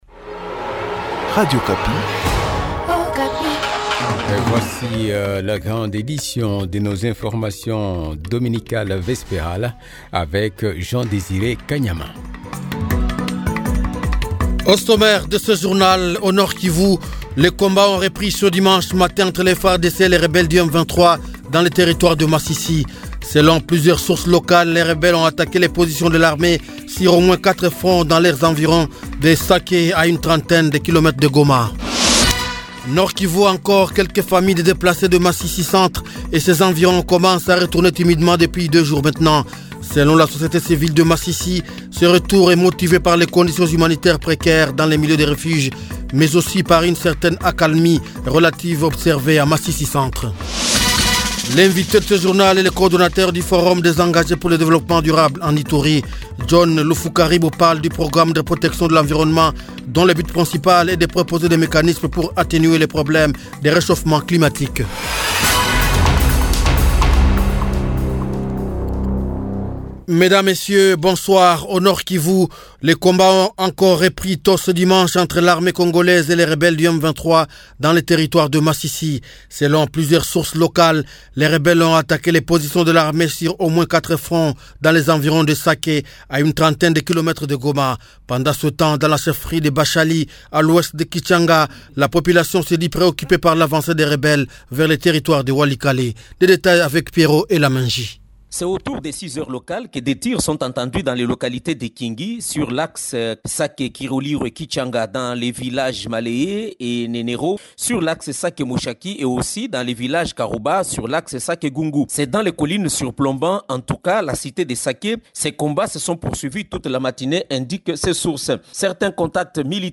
Edition de Dimanche 05 Mars 2023 Soir 18 heures